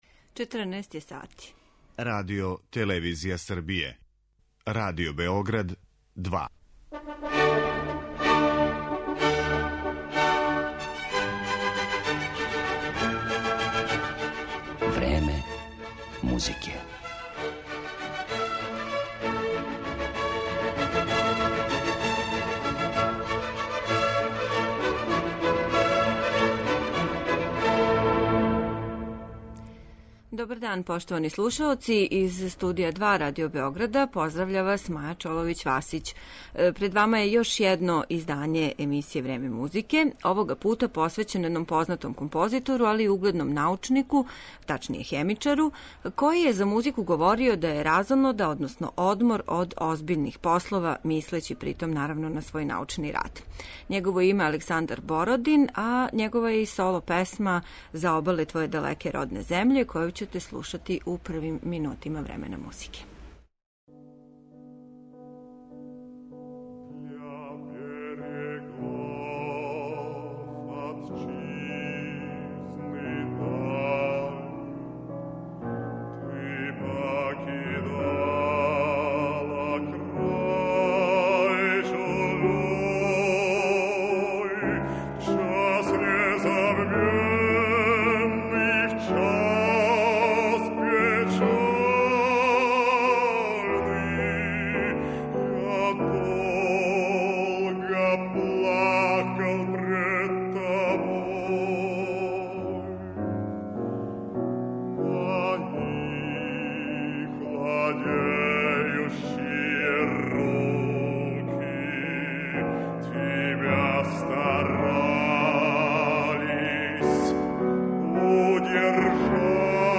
Његово је име Александар Бородин, а композиције из којих ћете бити у прилици да чујете познате фрагменте су Богатирска симфонија, симфонијска слика У степама централне Азије, Гудачки квартет бр. 2 и опера Кнез Игор.